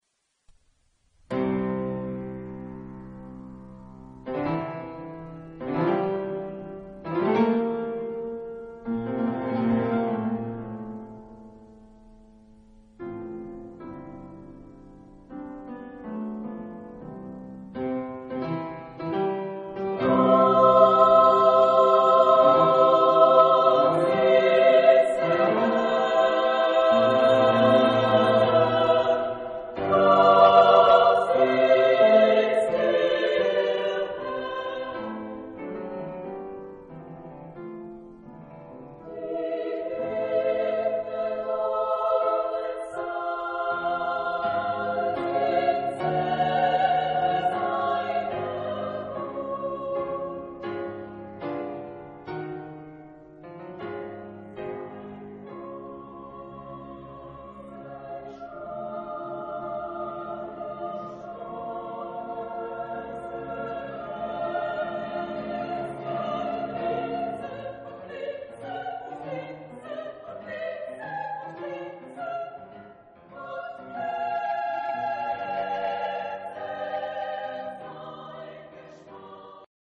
Genre-Style-Forme : Romantique ; Sacré ; Motet
Type de choeur : SSAA  (4 voix égales de femmes )
Instruments : Piano (1)
Tonalité : do majeur